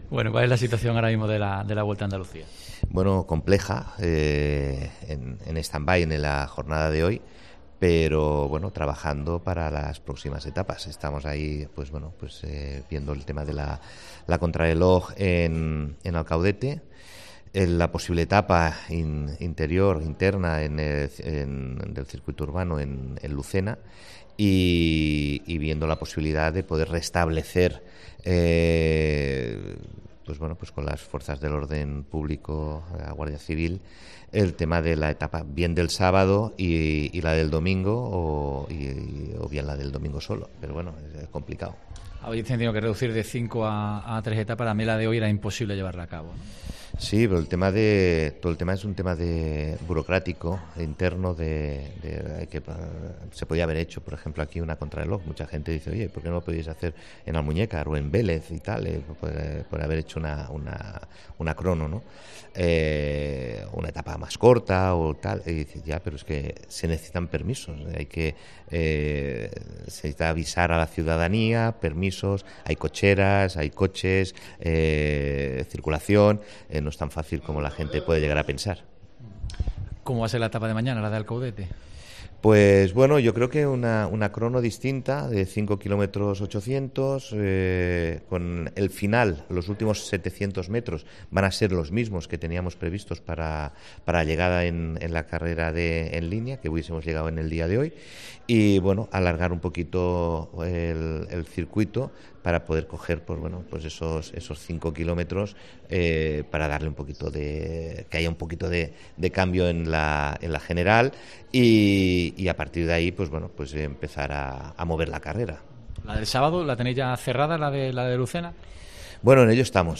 atiende los micrófonos de COPE Andalucía